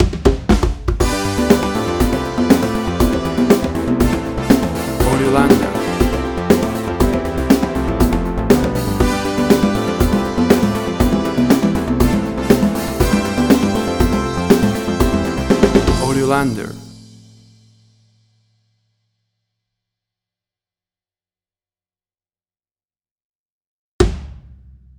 FANFARRIA TV SHOW, SYNTH POP
Tempo (BPM): 120